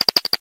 tuxpaint-pencil-sharpener
sharpen.ogg